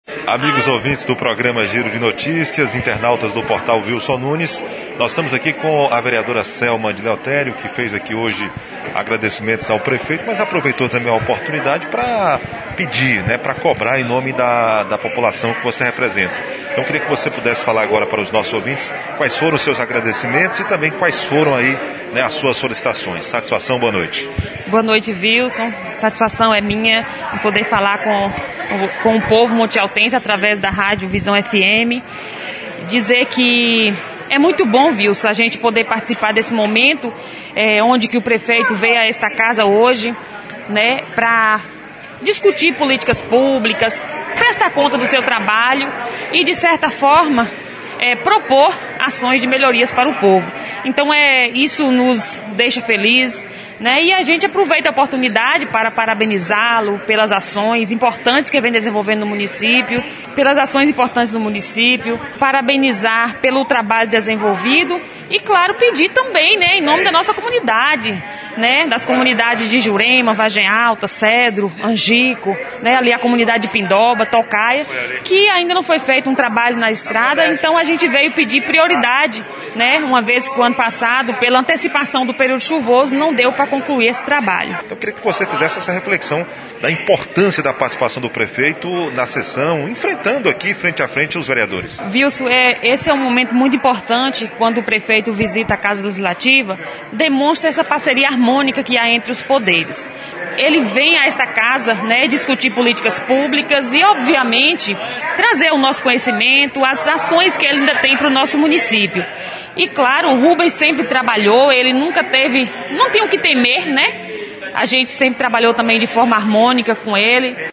Após o encerramento da sessão
entrevista